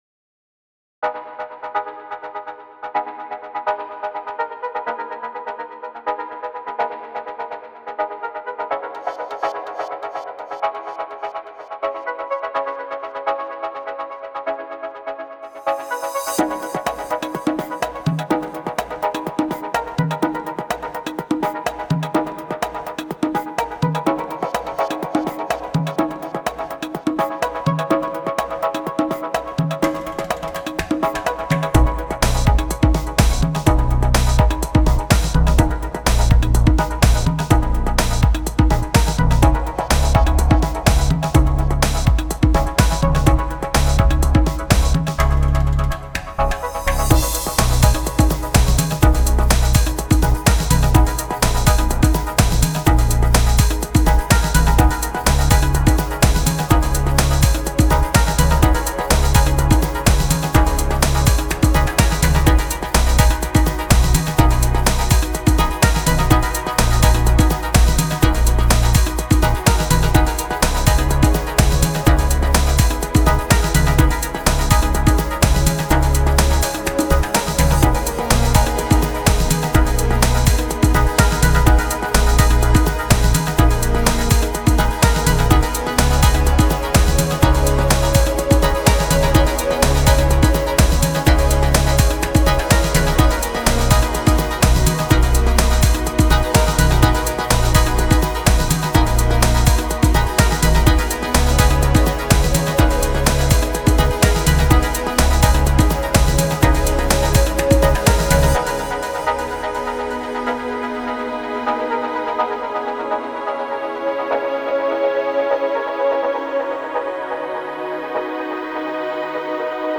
A calm, easy listening, Cafe del Mar like track.